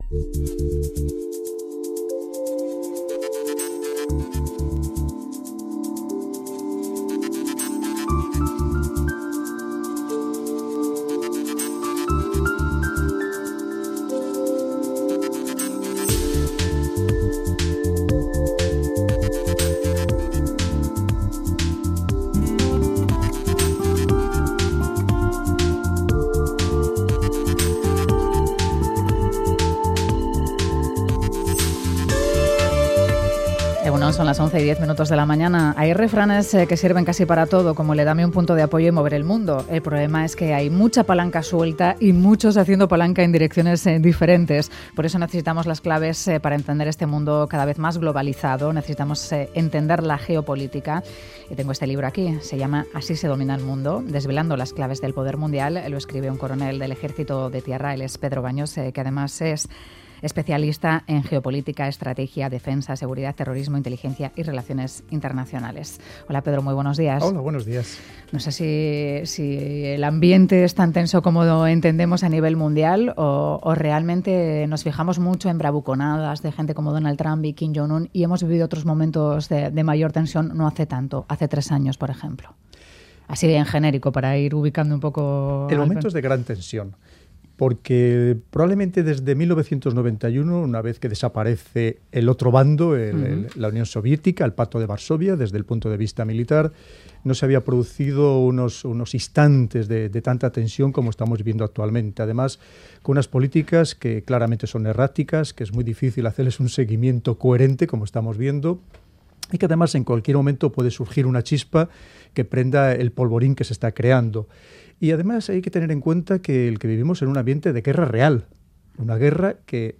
Pedro Baños, Coronel del Ejército de Tierra en la reserva y diplomado del Estado Mayor, ha dado las claves sobre geopolítica y geoestragegia en 'Boulevard'.